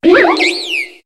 Cri de Munna dans Pokémon HOME.